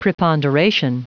Prononciation du mot preponderation en anglais (fichier audio)
Prononciation du mot : preponderation